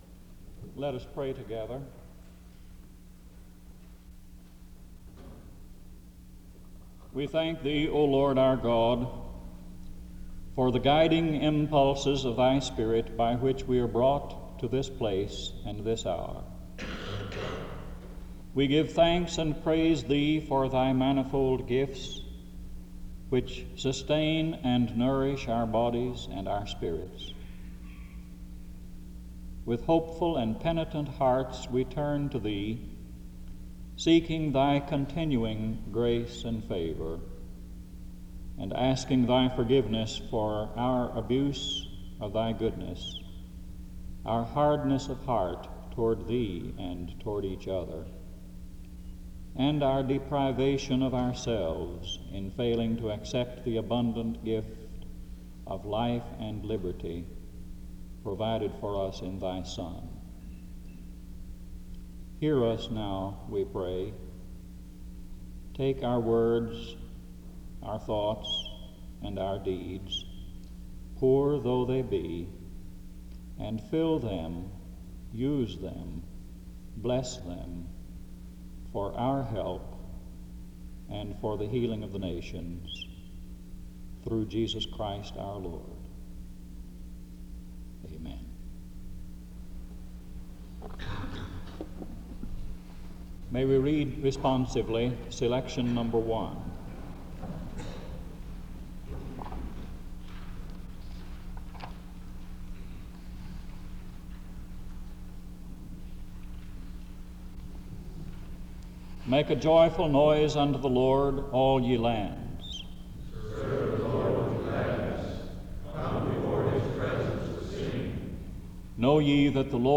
Location Wake Forest (N.C.)
SEBTS Chapel and Special Event Recordings - 1970s